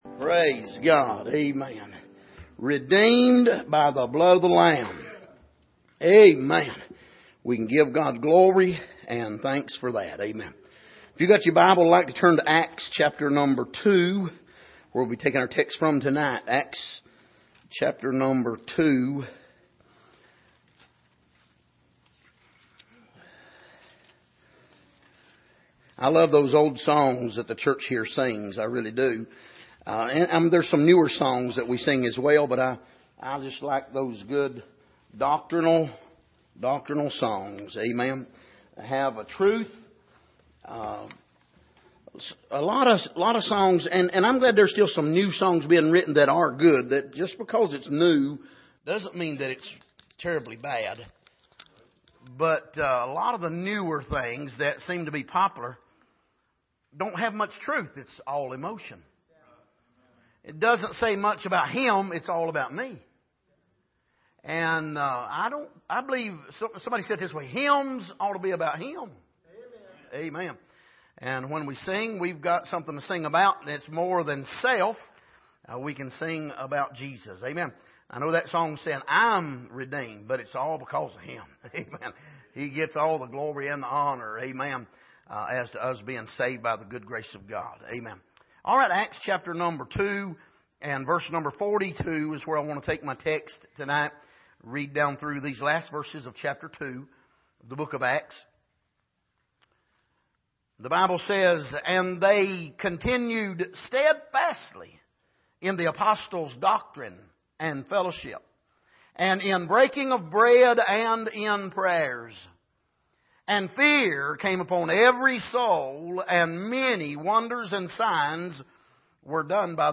Passage: Acts 2:42-47 Service: Sunday Evening